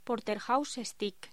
Locución: Porterhouse steak
voz